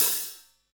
HAT H.H.LO06.wav